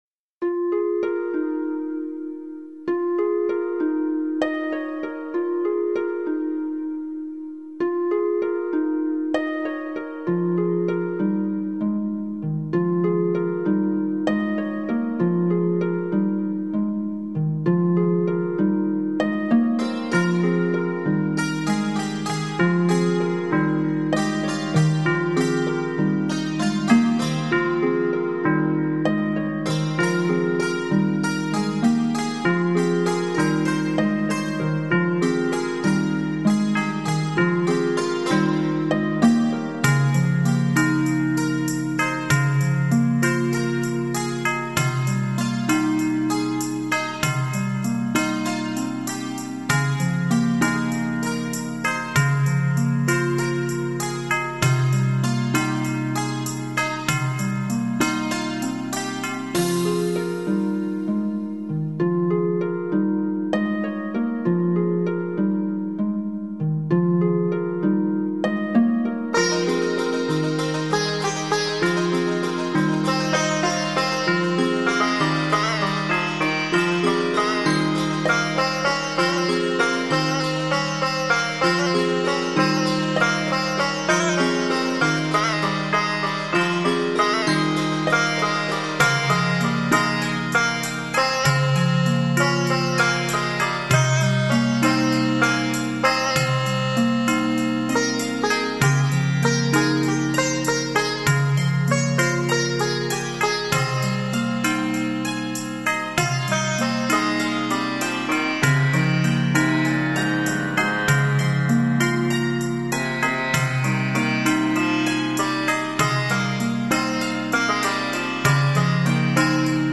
Жанр: Electronic, Ambient